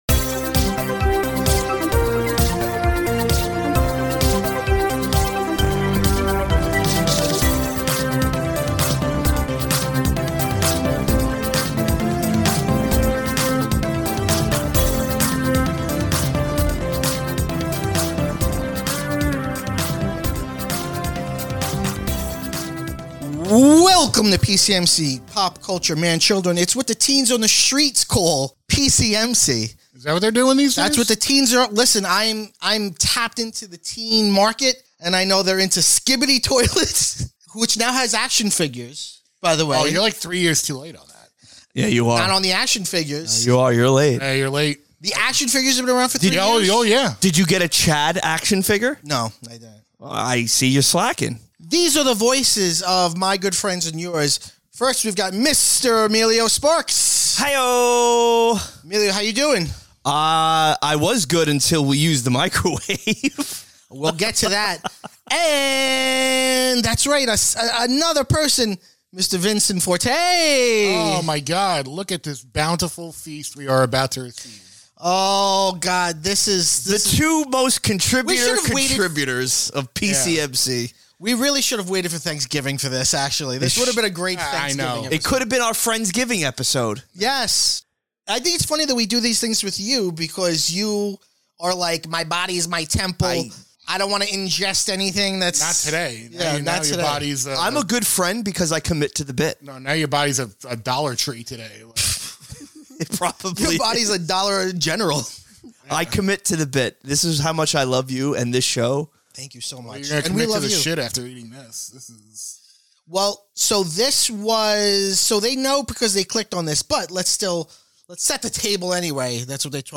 FYI: This episode contains very limited chewing for those of you who get grossed out!